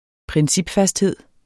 Udtale [ -ˌfasdˌheðˀ ]